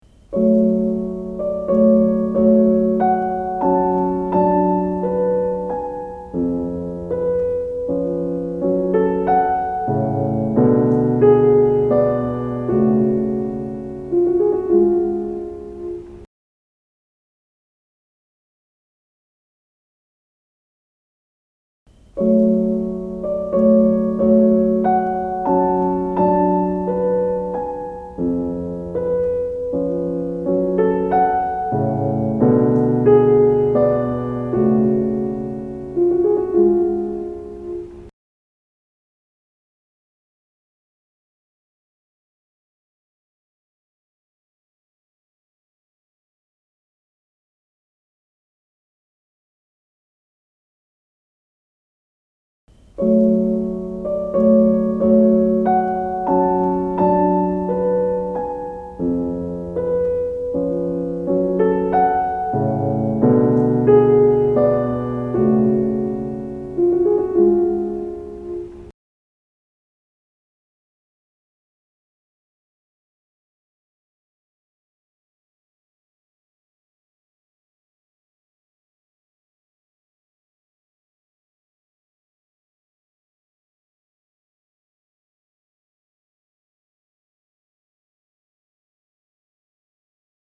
Du får höra varje exempel tre gånger efter varandra med allt längre tid emellan.